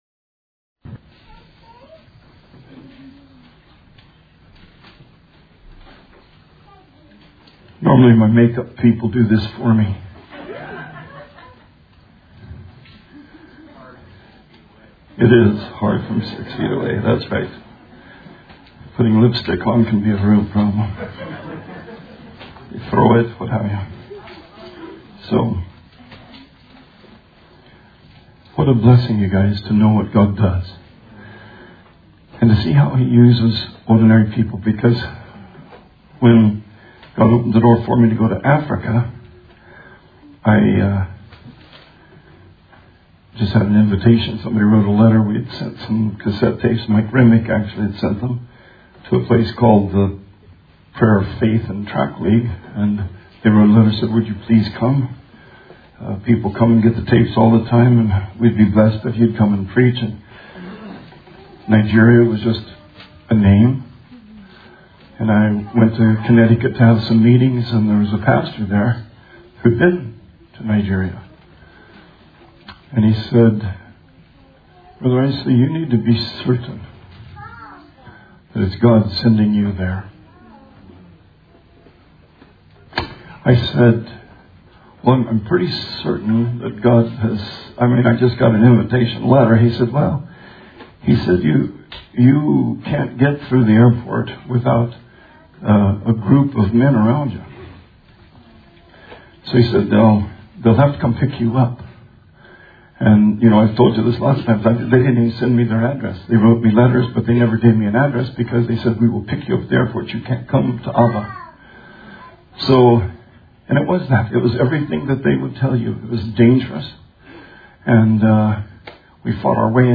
Sermon 6/13/20